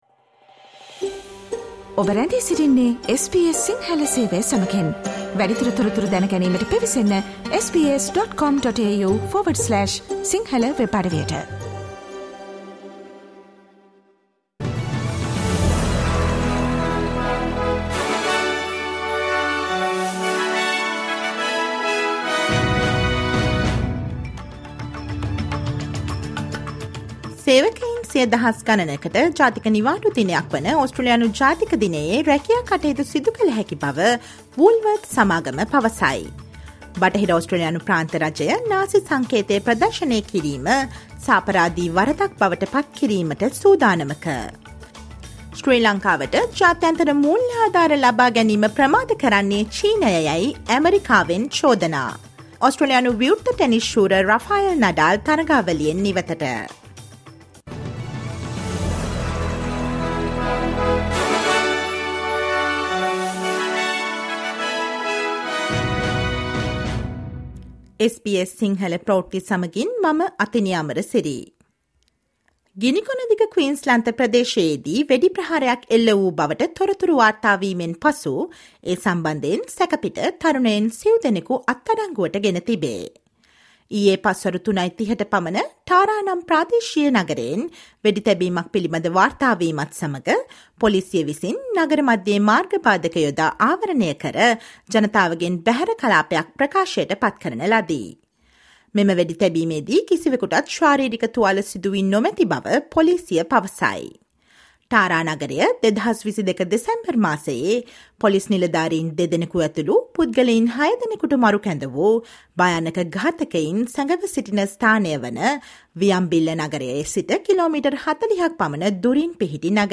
Listen to the latest news from Australia, Sri Lanka, and across the globe, and the latest news from the sports world on the SBS Sinhala radio news bulletin on Monday, Tuesday, Thursday, and Friday at 11 am.